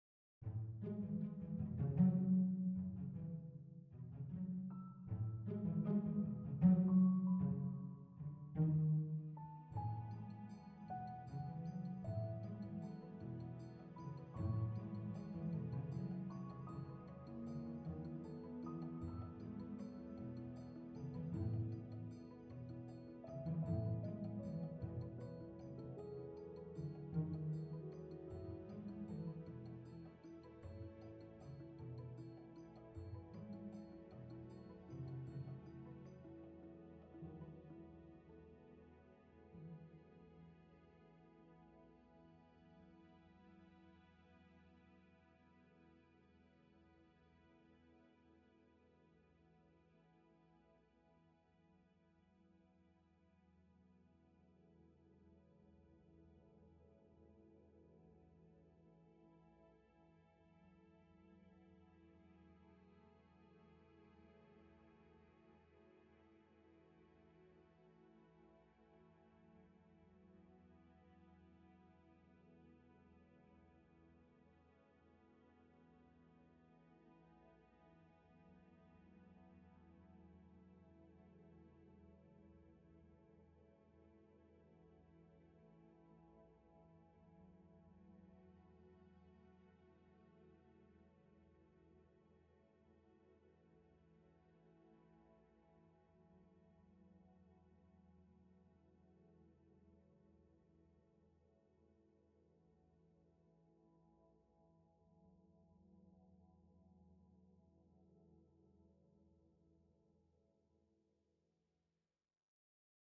Rose Cross Waltz 155bpm.mp3